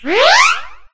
Heal2.ogg